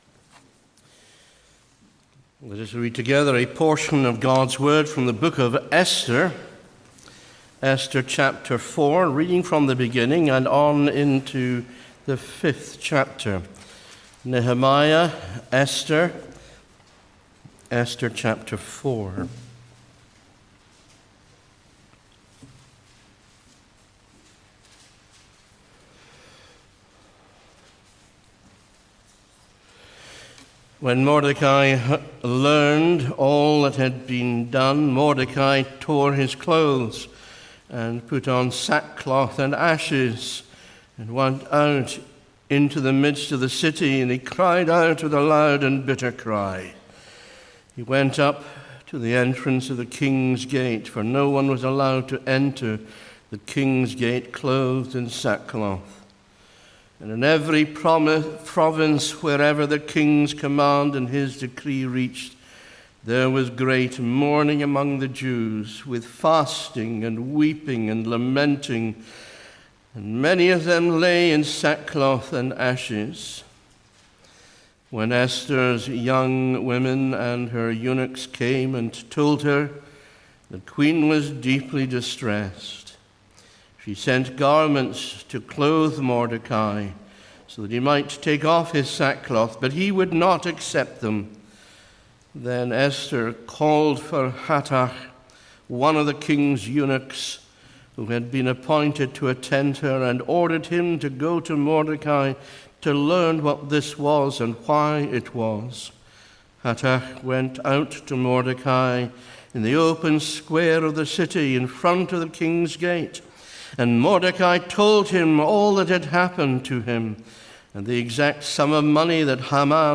Knox Church Perth
The Royal Sceptre | SermonAudio Broadcaster is Live View the Live Stream Share this sermon Disabled by adblocker Copy URL Copied!